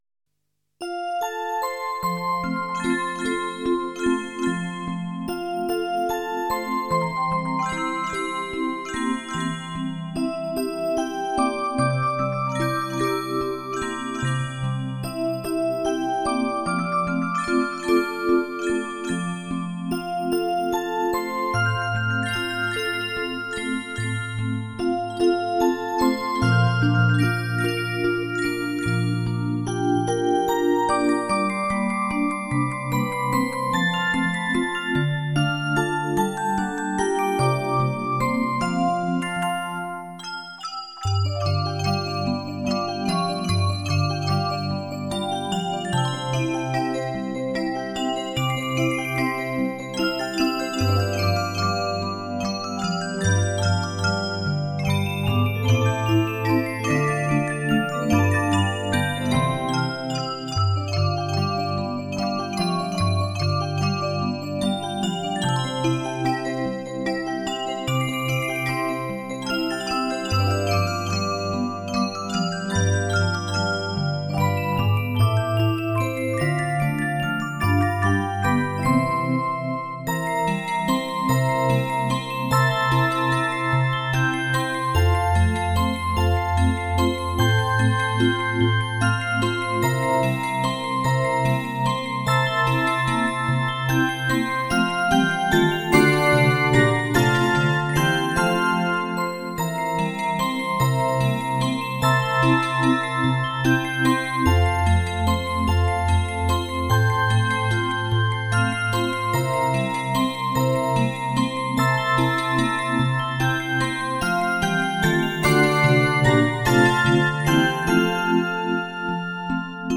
重迭的和声、飘渺的歌吟、传说般的回响……
本曲以清新如歌的旋律和轻松活泼的节奏，使你的灵魂心处有鲜活的临场感觉。